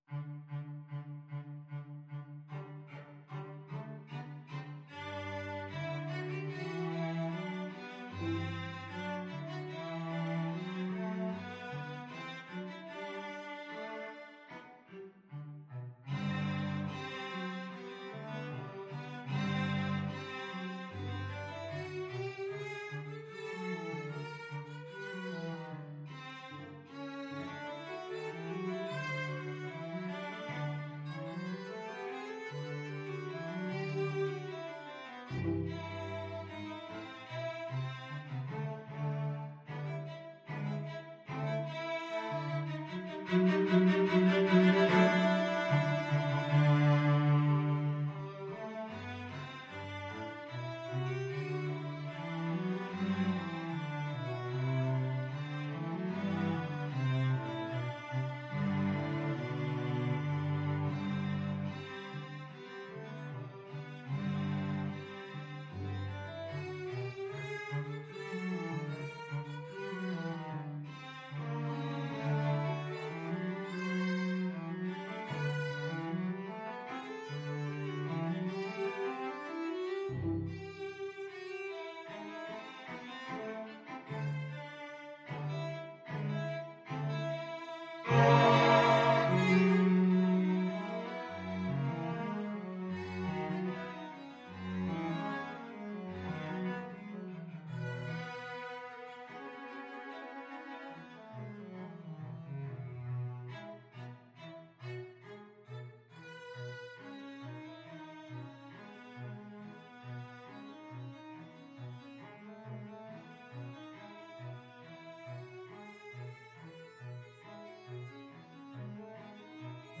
2/2 (View more 2/2 Music)
GemŠchlich (nicht eilen) =150
Cello Duet  (View more Advanced Cello Duet Music)
Classical (View more Classical Cello Duet Music)